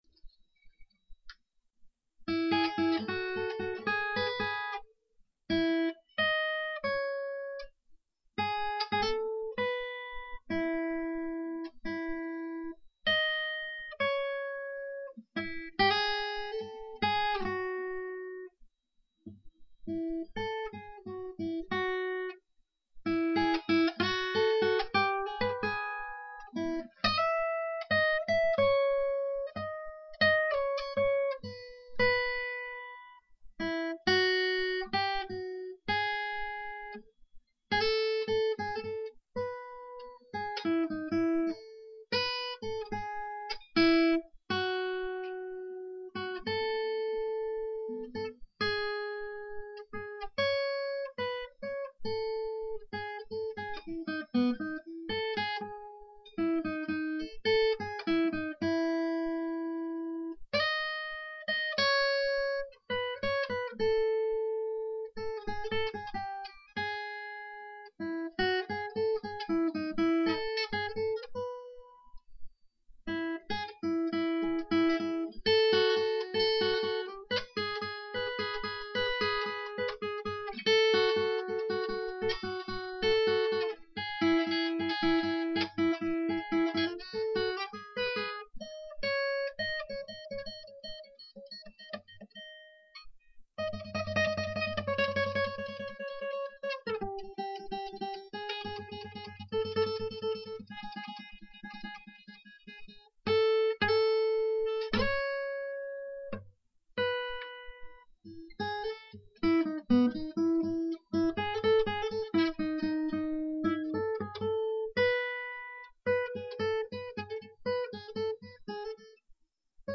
Tags: mandolin jam music